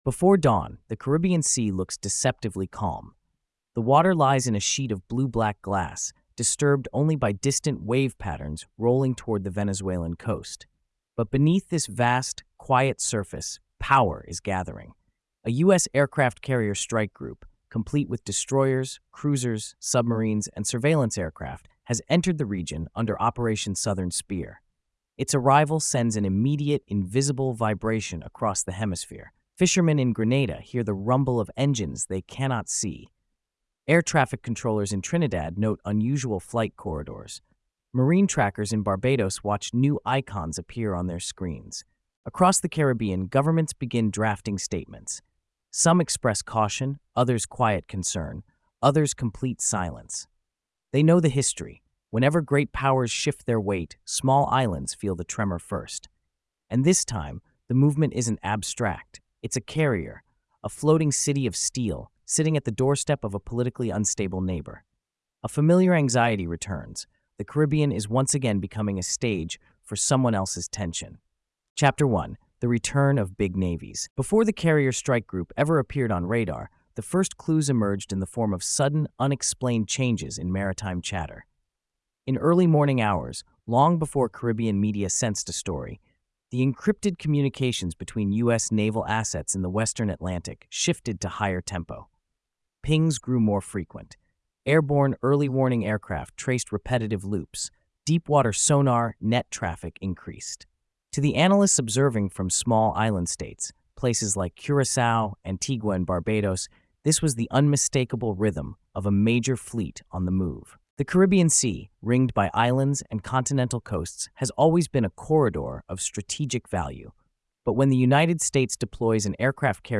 Told in a vivid third-person narrative, the episode reveals how Operation Southern Spear unfolded across a region already shaped by colonial memory, fragile economies, and longstanding territorial disputes. The story begins with the carrier’s dramatic entry into the basin, then follows its ripple effects across Barbados, Trinidad, Jamaica, The Bahamas, and Guyana.